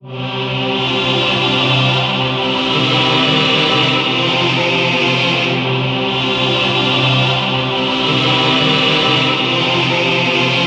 黑暗钢琴
描述：柜子陷阱钢琴和合唱团
Tag: 140 bpm Trap Loops Piano Loops 2.31 MB wav Key : Unknown FL Studio